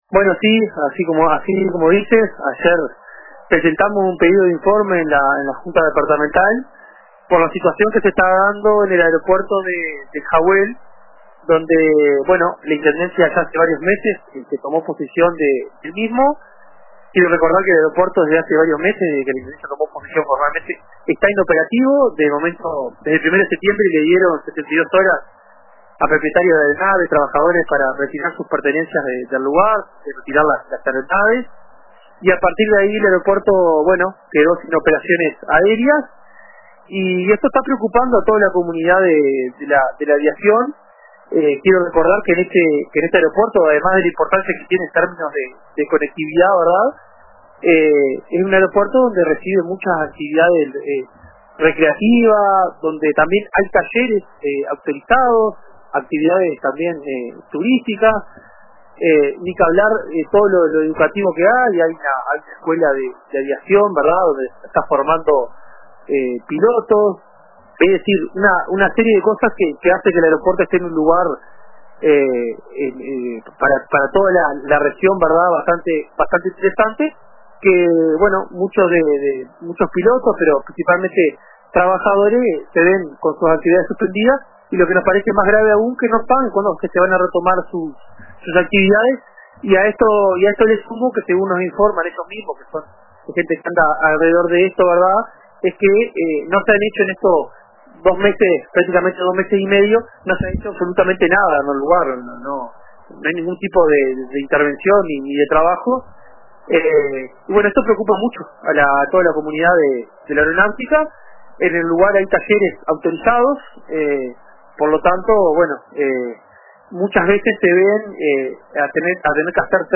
En diálogo con el programa “Radio con Todo” de RBC, Urdangaray expresó su preocupación por la falta de avances desde la clausura del aeródromo.